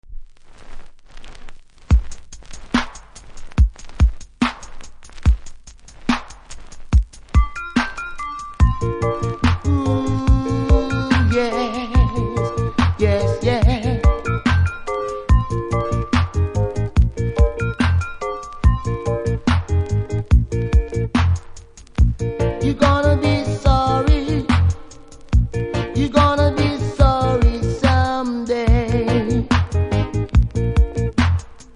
REGGAE 80'S
スタジオ１系から出ていた曲のセルフリメイク♪